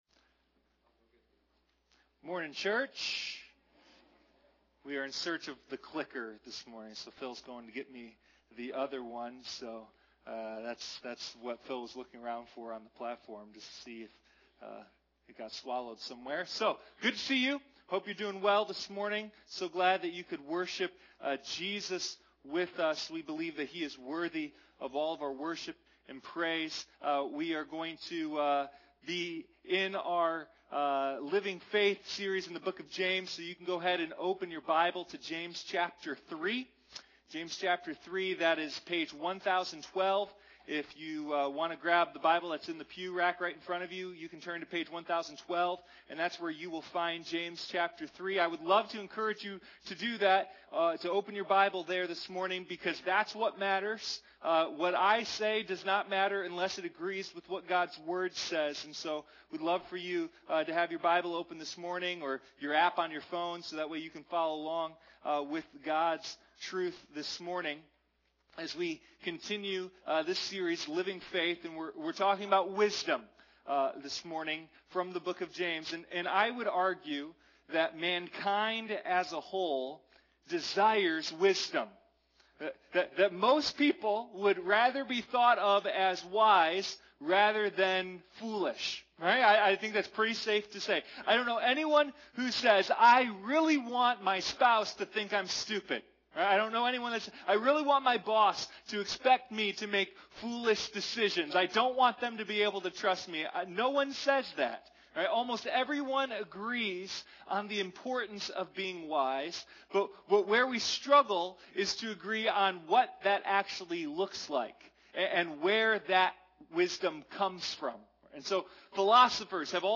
Sunday Morning Living Faith: The Book of James